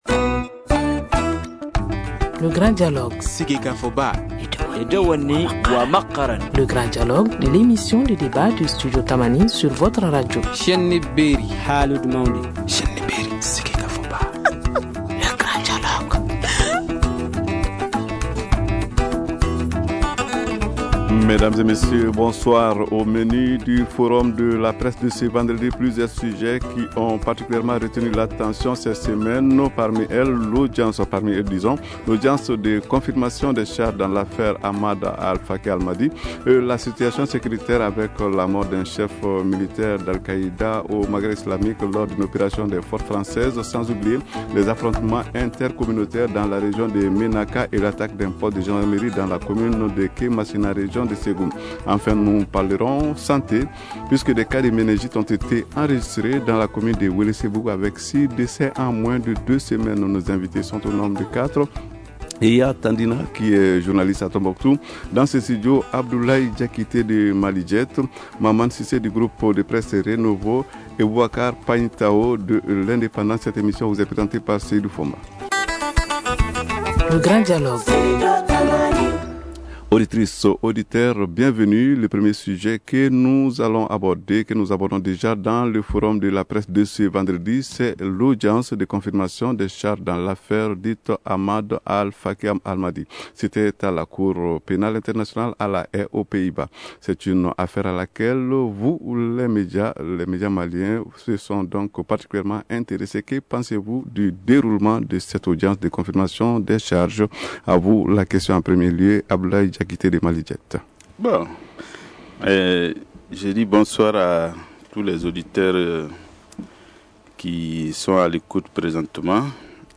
Nous allons revenir sur ces différents sujets avec quatre invités.